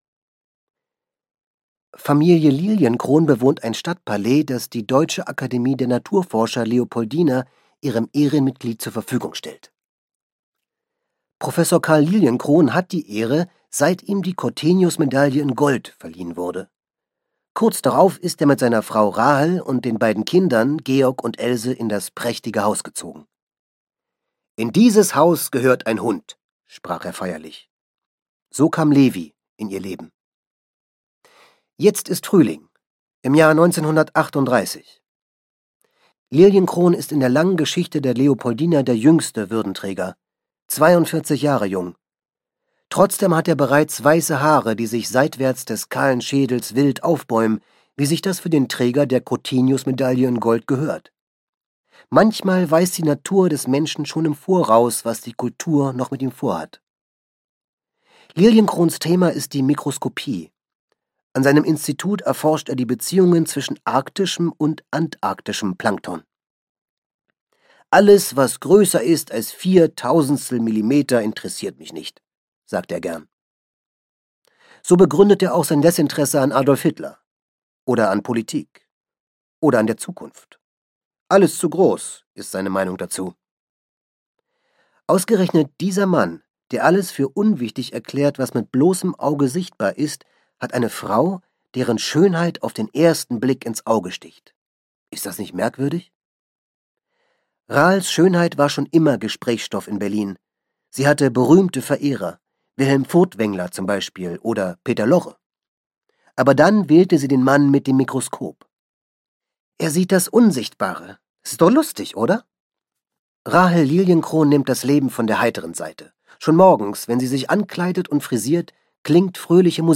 Sirius - Jonathan Crown - Hörbuch - Legimi online